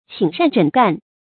寝苫枕干 qǐn shān zhěn gàn
寝苫枕干发音